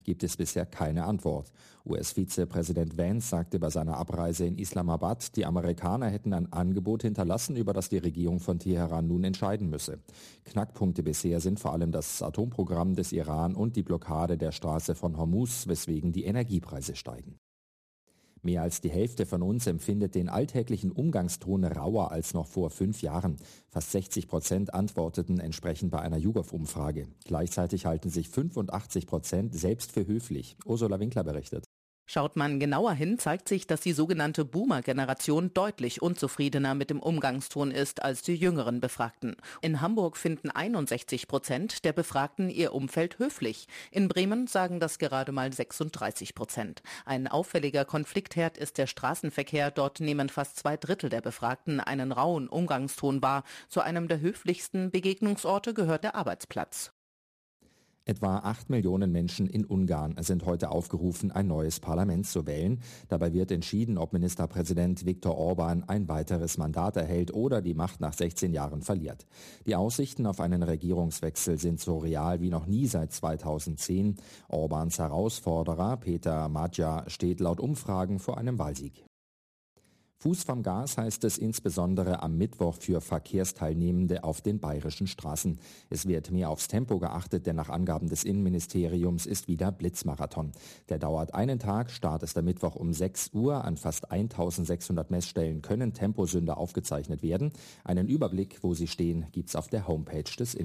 Nachrichten , Nachrichten & Politik